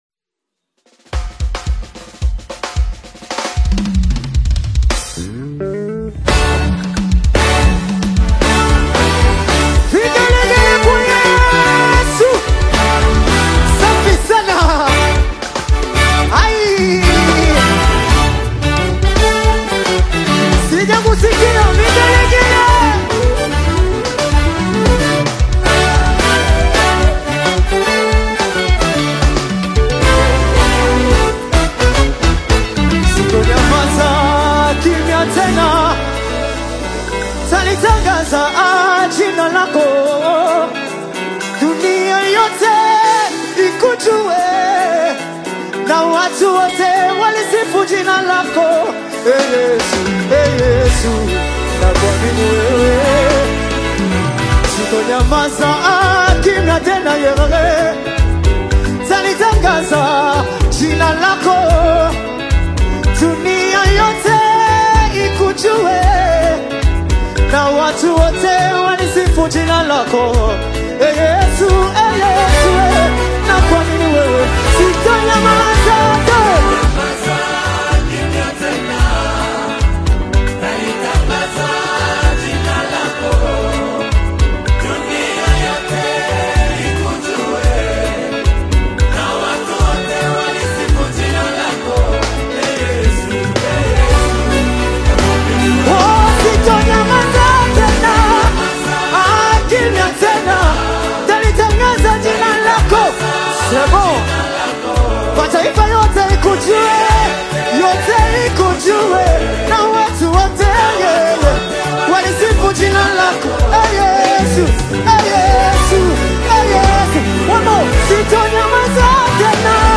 is an uplifting Tanzanian gospel single
vibrant African gospel rhythms
passionate choral harmonies
contemporary African praise sound